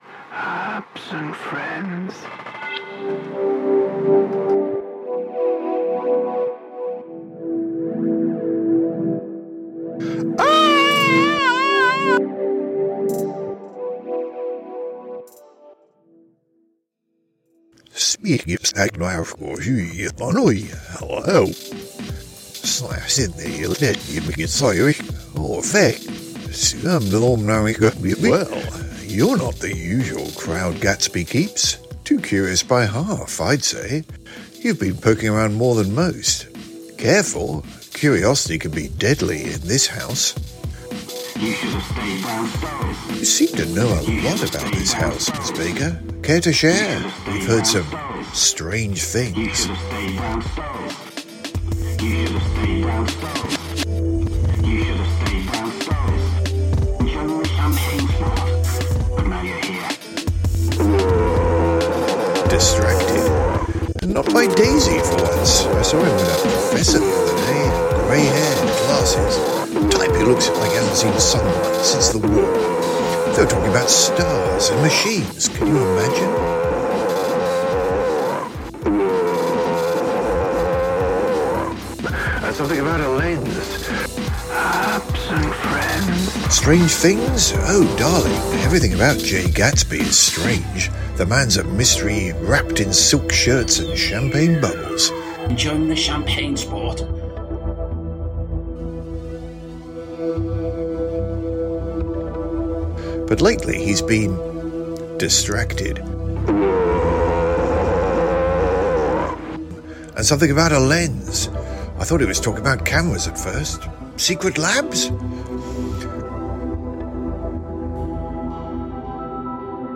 They were there in spirit as they provided the sound effects for the alternate dimension that players travelled through during the fractured time. They diligently recorded a script of nonsense, that only bore a passing resemblance to what was going on in the scenario.